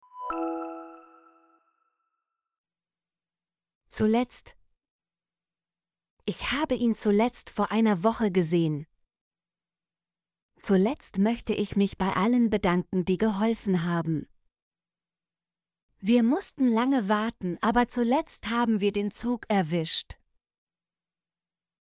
zuletzt - Example Sentences & Pronunciation, German Frequency List